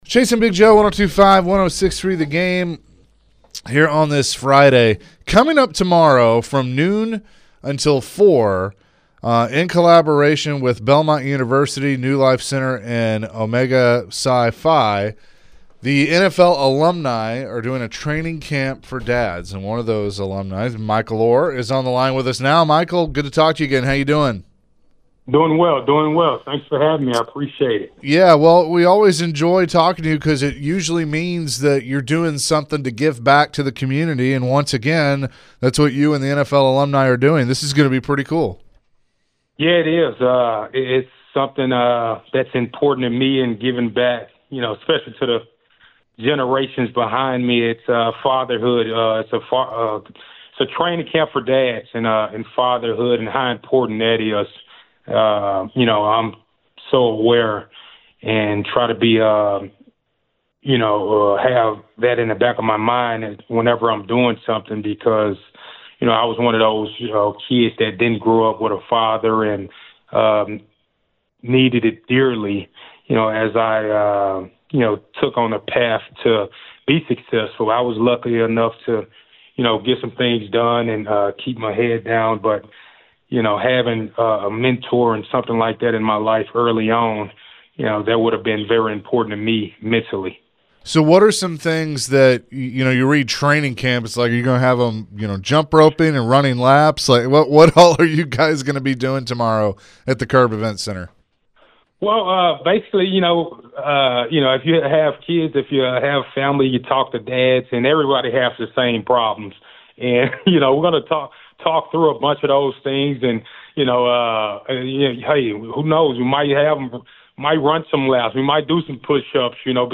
Former Titans O-Lineman and Michael Oher joined the show to talk about the upcoming NFL Alumni event this weekend. Later in the conversation, Michael shared his thoughts on the young QB in Cam Ward and what Bill Callahan brings to the O-Line group.